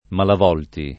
Malavolti [ malav 0 lti ] cogn.